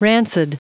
Prononciation du mot rancid en anglais (fichier audio)
Prononciation du mot : rancid